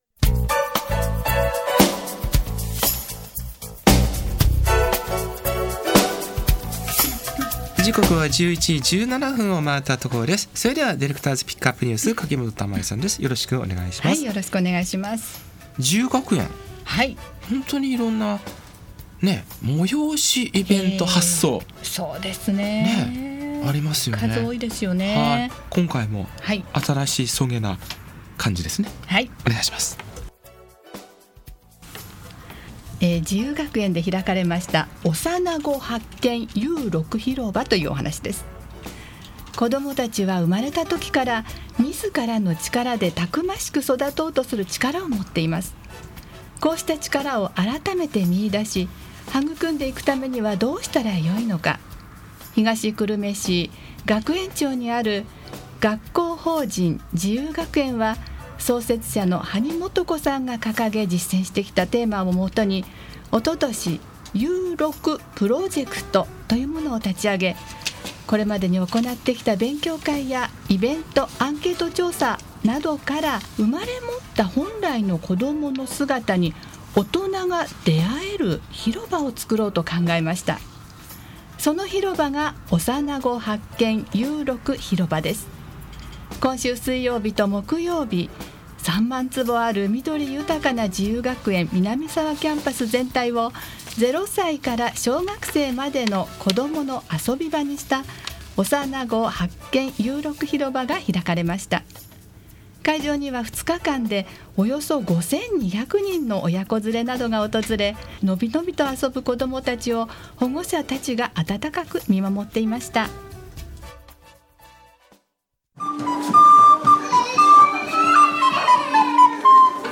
11月22日（水）・23日（木･祝）の2日間、自由学園で開催した「おさなご発見U6ひろば」の様子を、23日の午後FM西東京が取材なさり、下記の通りラジオ番組内で放送されました。
■「音とあそぼう」の「うさぎの音楽座」の様子ほか